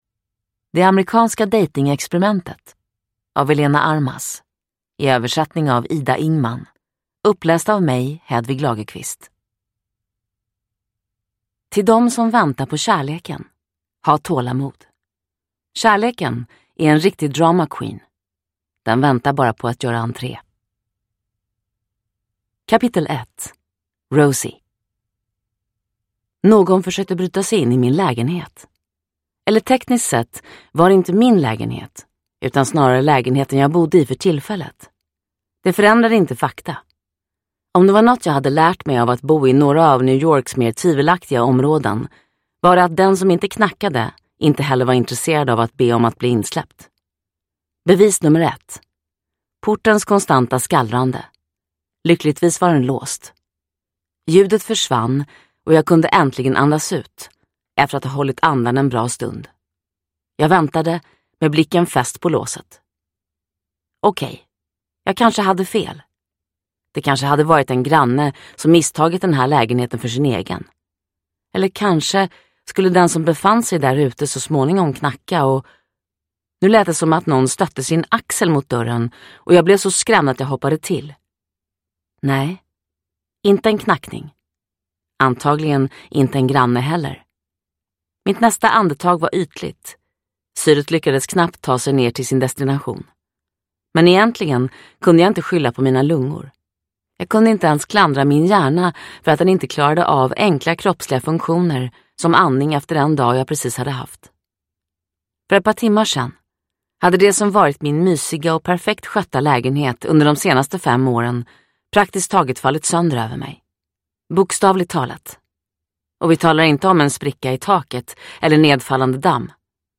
Det amerikanska dejtingexperimentet (ljudbok) av Elena Armas